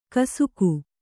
♪ kasuku